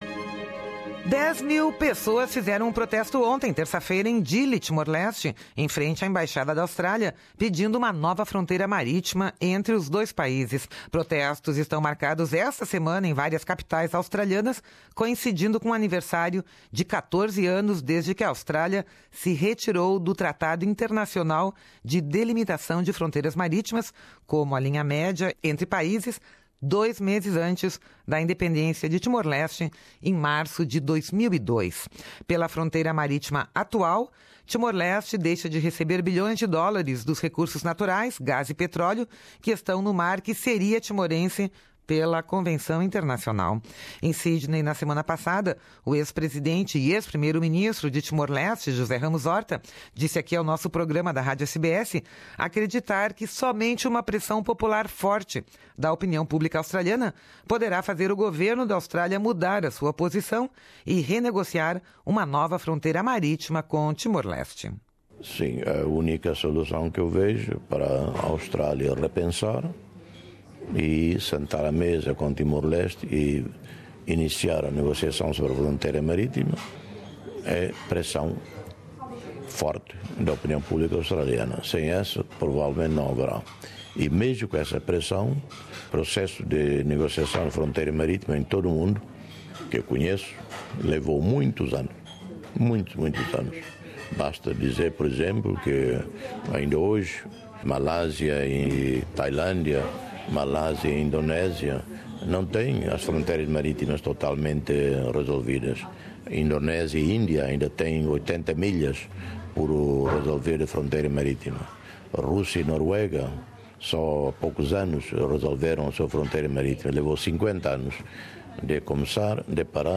Com muitos protestos marcados esta semana em várias capitais australianas, José Ramos-Horta disse à Rádio SBS acreditar que somente uma pressão popular, muito forte, da opinião pública, poderá fazer o governo da Austrália mudar a sua posição e renegociar uma nova fronteira marítima com Timor-Leste. O Prêmio Nobel da Paz e ex-presidente d e Timor-Leste falava ao Programa Português da Rádio SBS na sua recente visita a Sydney.